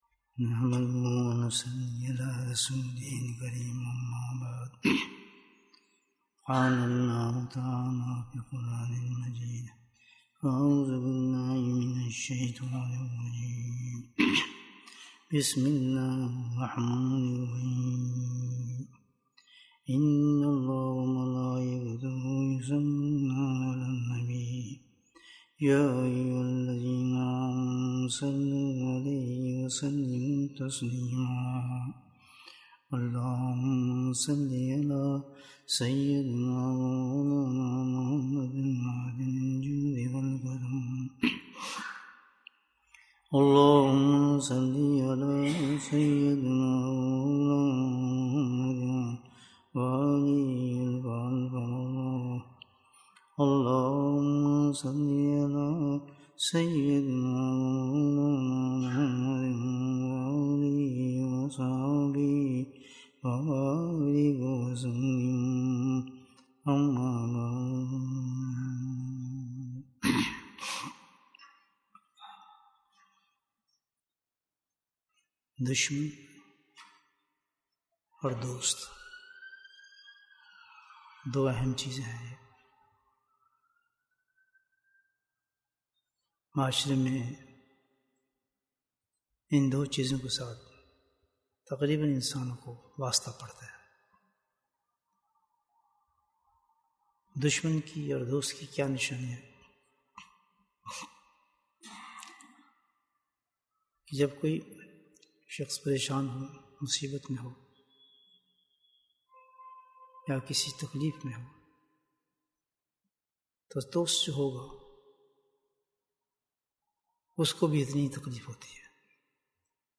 Bayan, 46 minutes 14th July, 2022 Click for English Download Audio Comments Allah hu Akbar 3rd Nov, 2022 چھوٹ جائے زمانہ کوئی غم نہیں ہاتھ مرا رہے بس تیرے ہاتھ میں سبحان اللہ سبحان اللہ 15th Jul, 2022